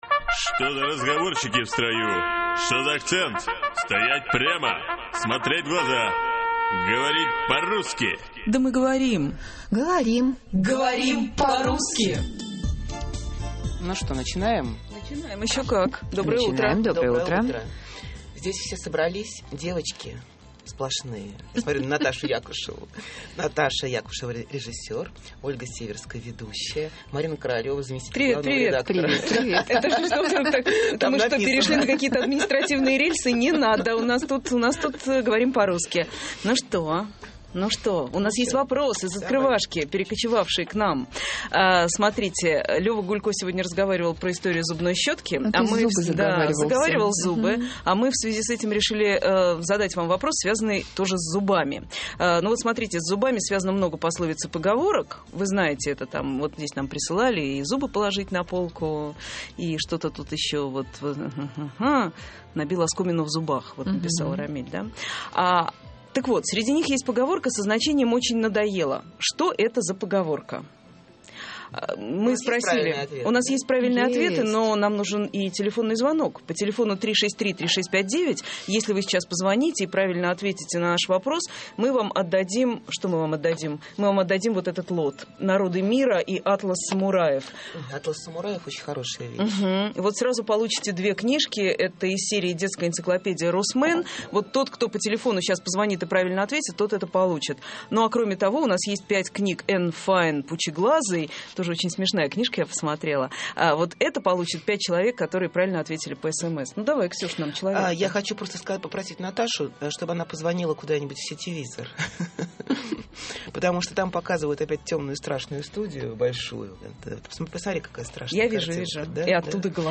Говорим по-русски. Передача-игра